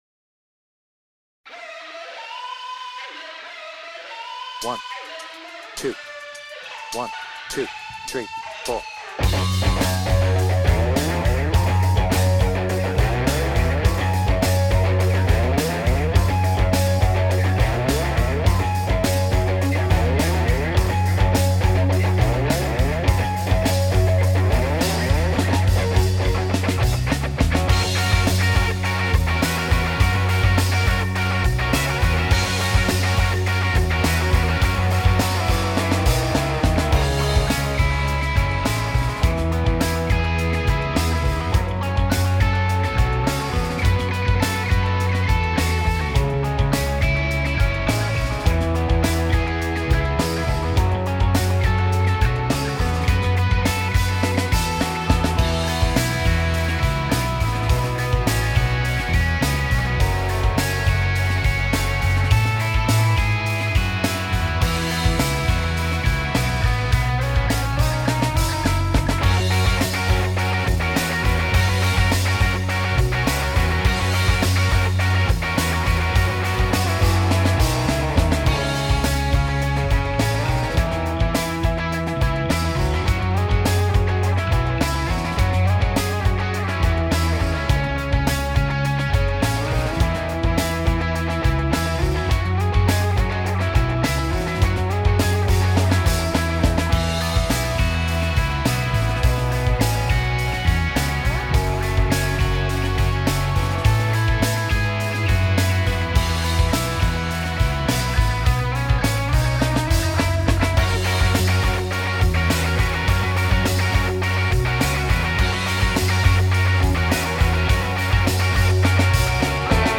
BPM : 104
Without vocals